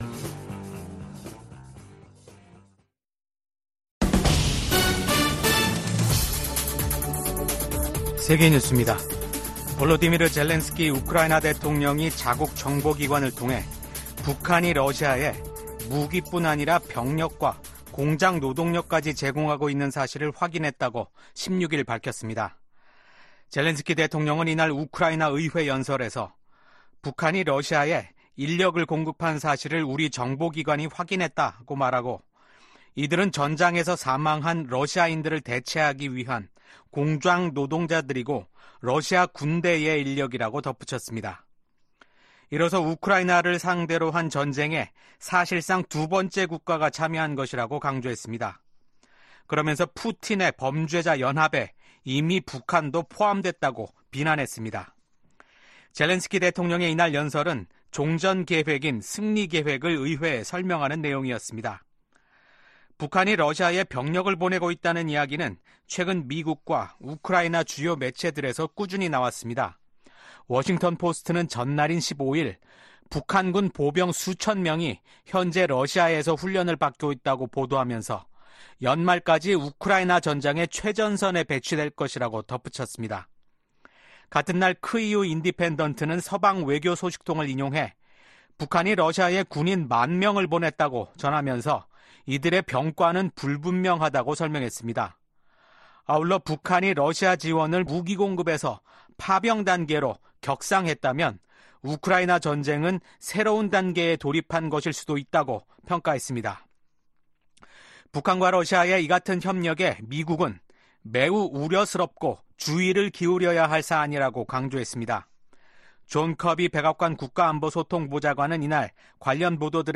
VOA 한국어 아침 뉴스 프로그램 '워싱턴 뉴스 광장' 2024년 10월 17일 방송입니다. 러시아의 방해로 해체된 유엔 대북 제재 감시의 공백을 메꾸기 위한 다국적 감시체제가 발족했습니다. 미국 정부는 북한이 한국과의 연결도로를 폭파하고 한국의 무인기 침투를 주장하며 군사적 대응 의사를 밝힌 데 대해 긴장 고조 행위를 멈출 것을 촉구했습니다.